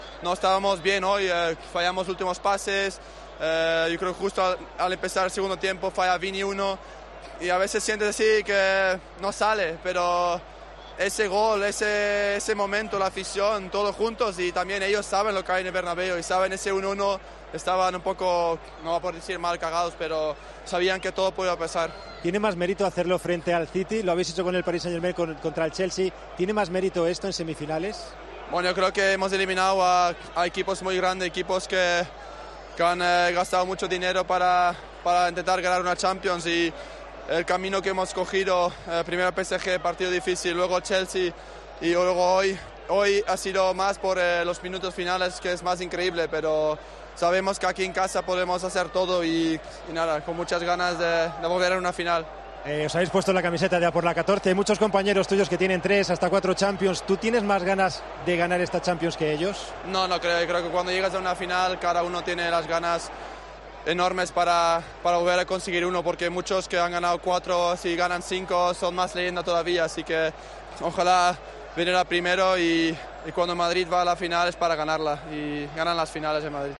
AUDIO: El portero del Real Madrid analizó en Movistar la clasificación de los blancos para la final de Champions tras una remontada heroica.